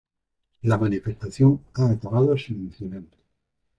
Pronounced as (IPA) /akaˈbado/